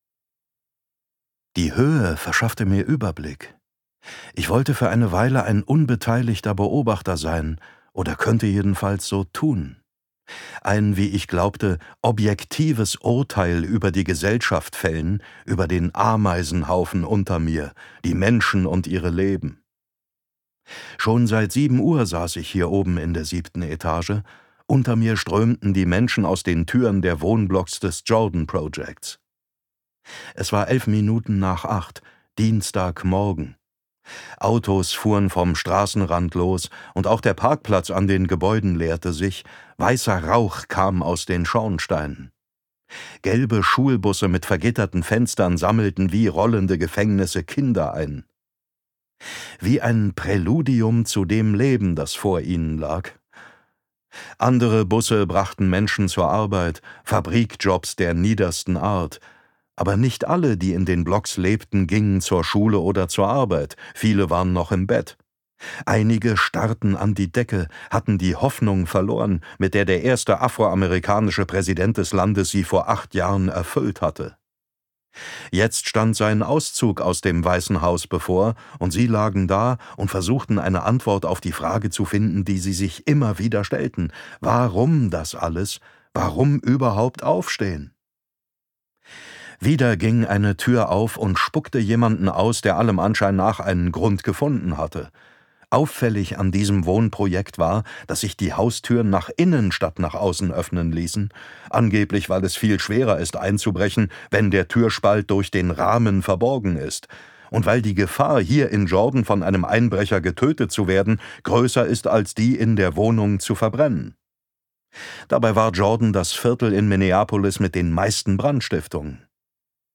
Details zum Hörbuch
Sprecher David Nathan